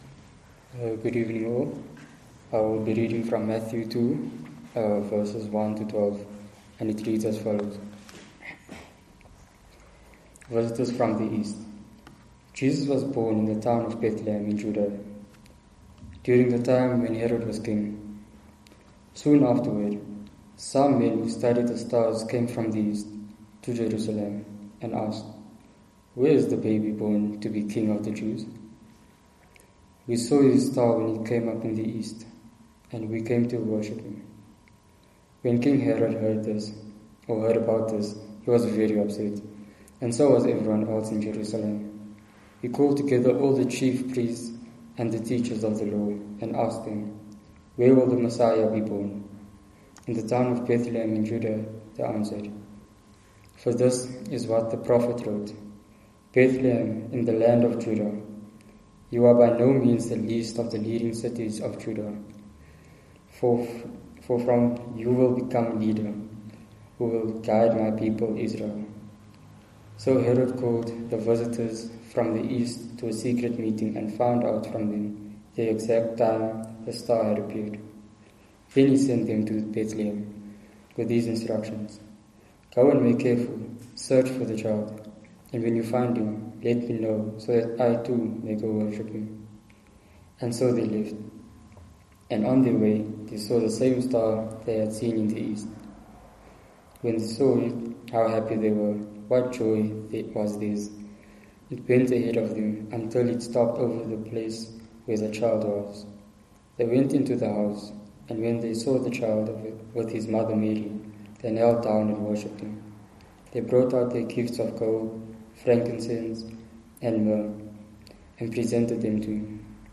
Lectionary Preaching - Second Sunday After Christmas - Trinity Methodist Church
Sermons